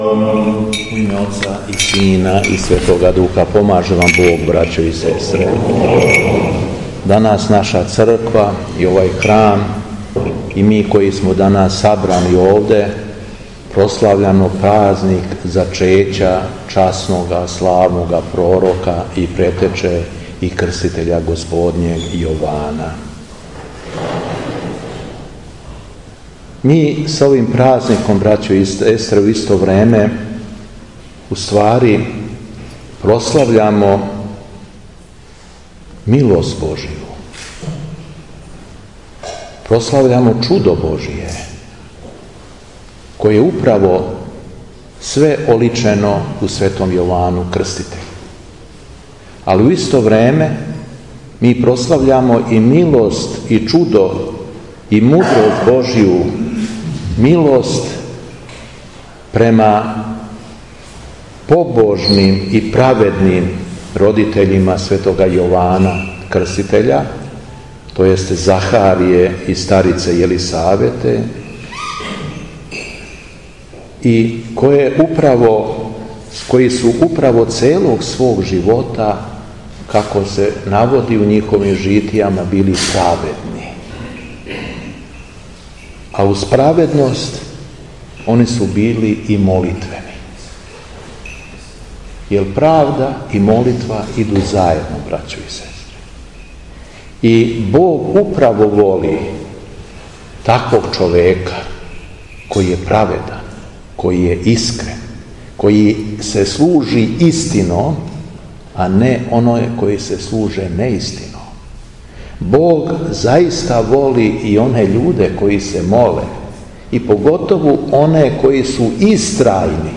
У уторак, 6. октобра 2020. године, када прослављамо празник Зачећа Светог Јована Крститеља, Његово Преосвештенство Епископ шумадијски Господин Јован је служио Свету Литургију у Јовановцу поводом славе храма.
Беседа Његовог Преосвештенства Епископа шумадијског Г. Јована